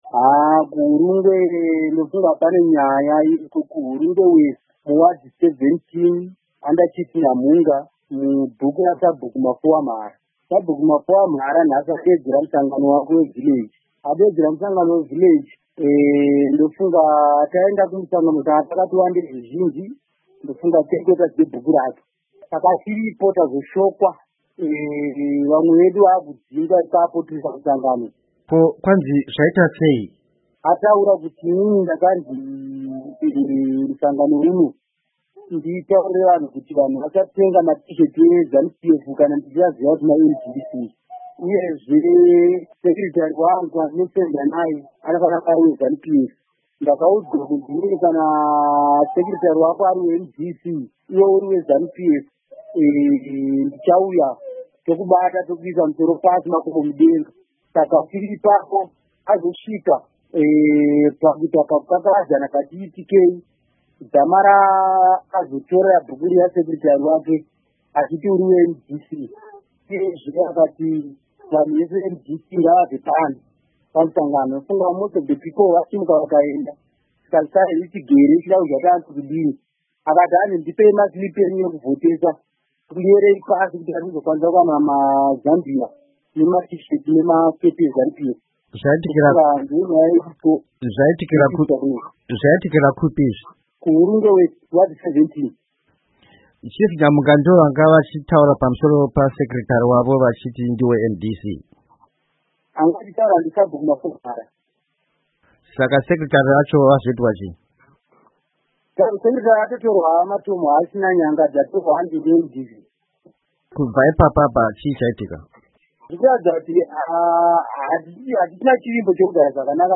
Hurukuro neMumwe Mutsigiri weMDC Alliance